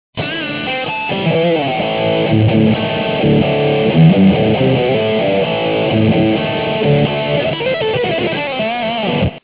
On the right, I took Thing Two to my part-time job at MARS Music one night and did some jamming on stage.
grind.mp3